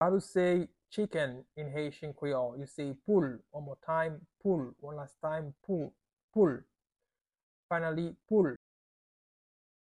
Listen to and watch “Poul” audio pronunciation in Haitian Creole by a native Haitian  in the video below:
How-to-say-Chicken-in-Haitian-Creole-Poul-pronunciation-by-a-Haitian-teacher.mp3